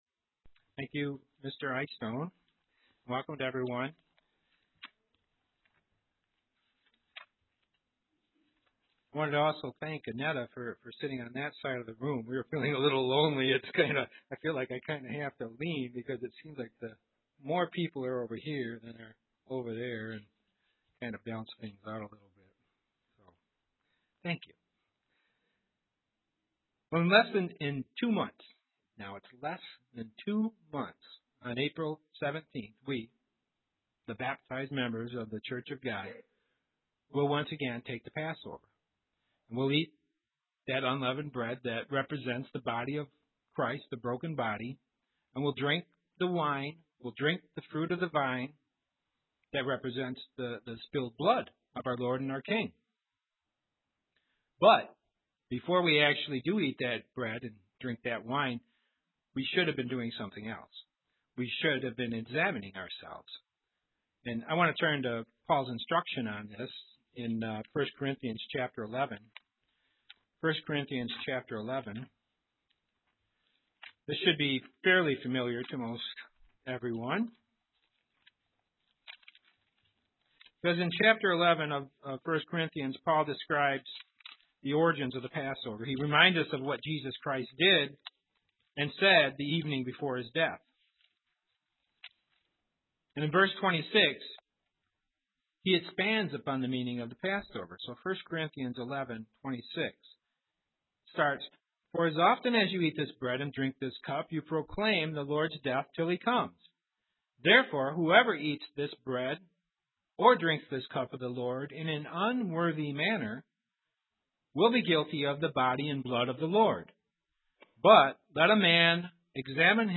UCG Sermon Studying the bible?
Given in Grand Rapids, MI